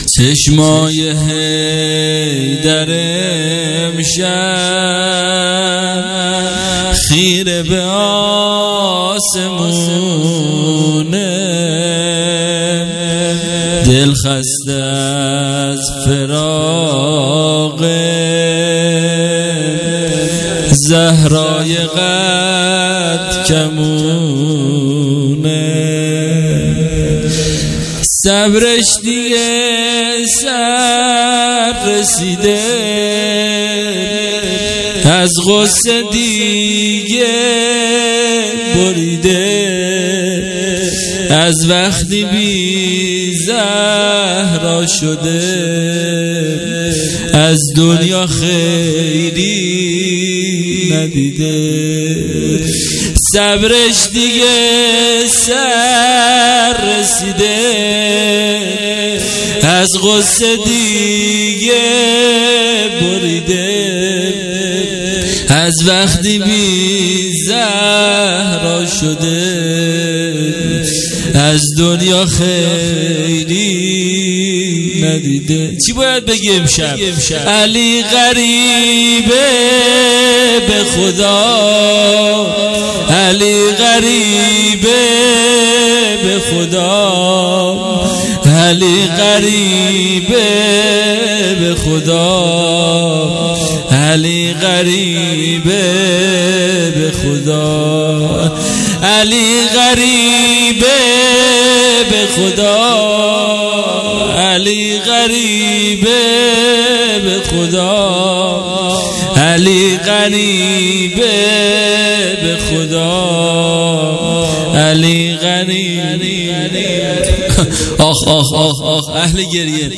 شب شهادت امام علی (ع) 99